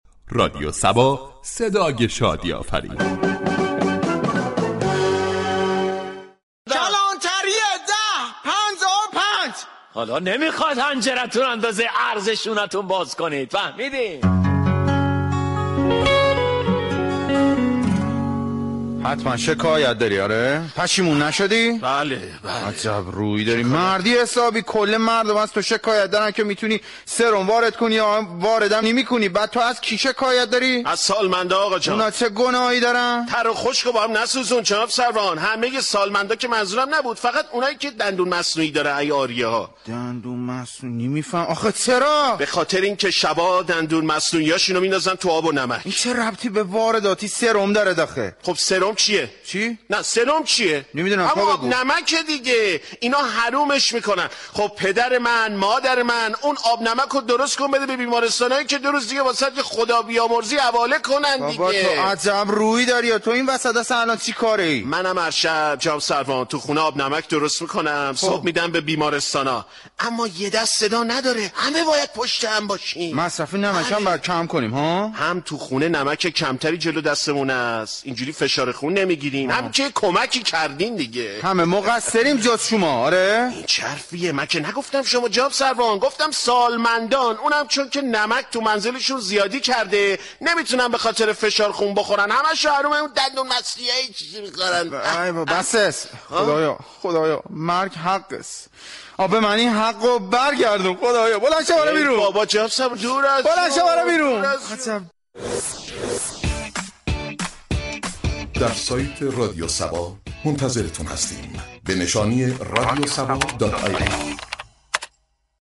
در بخش نمایشی شهر فرنگ با بیان طنز به موضوع كمبود سرم در كشور پرداخته شده است ،در ادامه شنونده این بخش باشید.